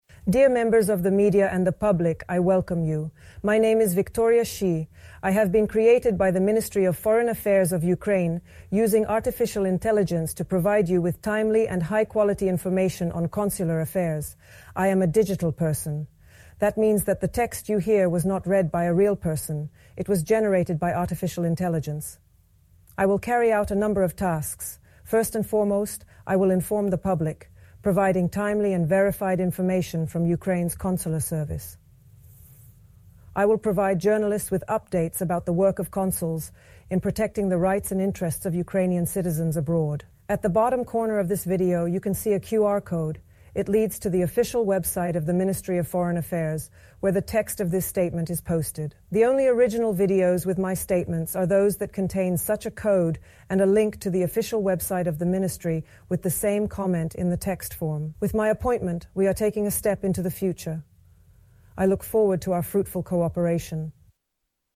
Inaugural Remarks Announcing Identity and Purpose Under Ukraine's Ministry of Foreign Affairs/Consular Affairs
Audio mp3 of Address in English       Audio AR-XE mp3 of Address in English